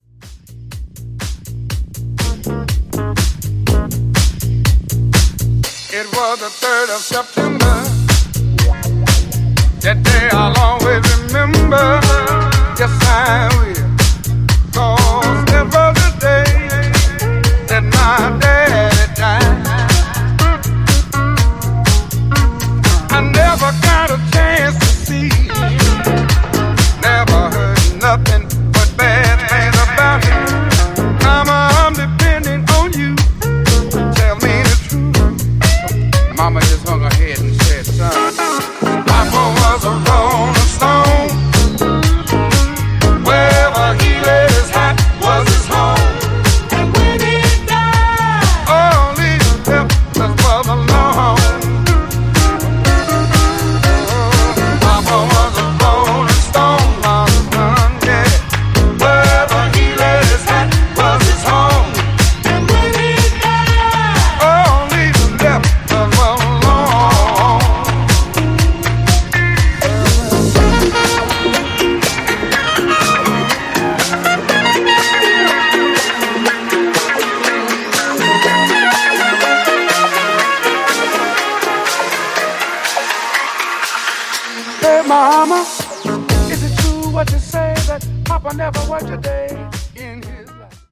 Two bona fide classic 70s cuts reworked for now.
relentlessly hammers the groove home